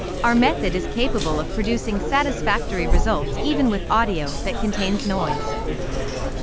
noisy_audio_cafeter_snr_0.wav